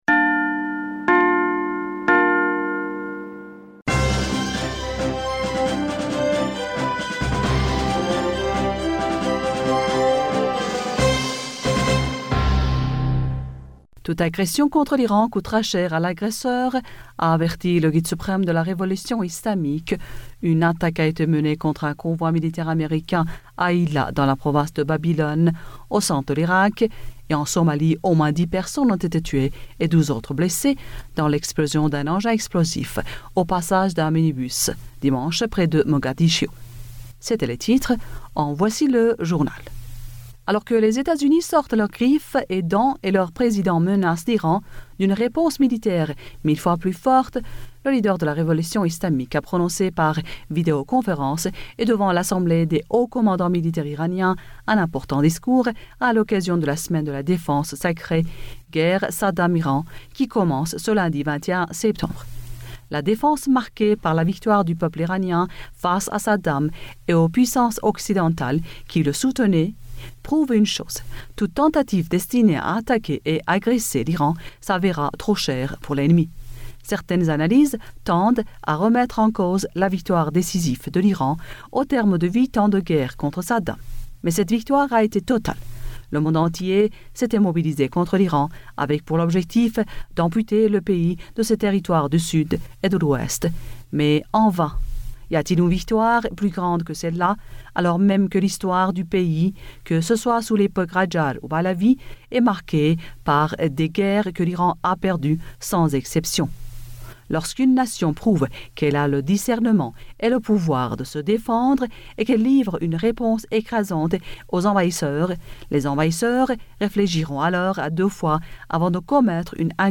Bulletin d'information du 21 septembre 2020